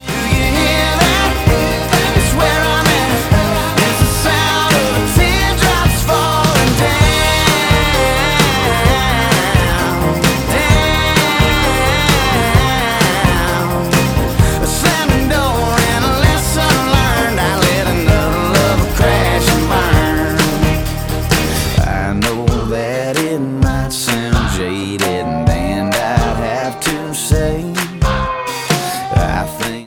• Country Ringtones
pop- and R&B-influenced sound